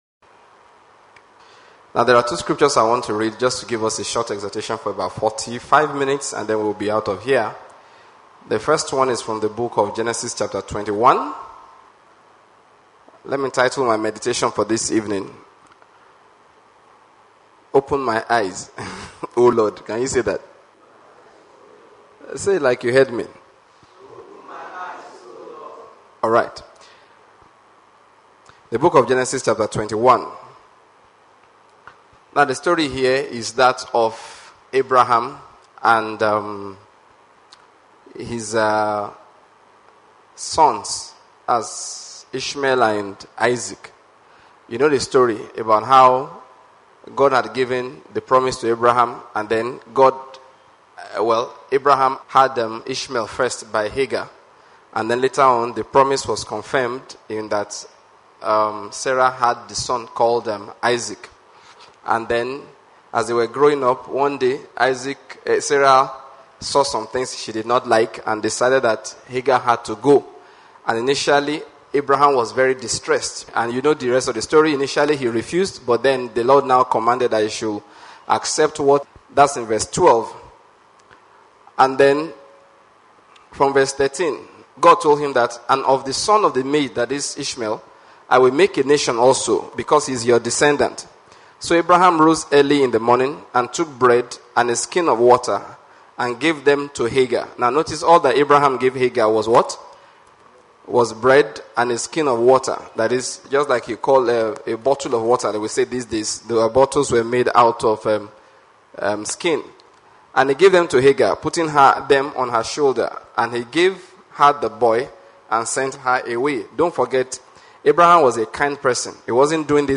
audio message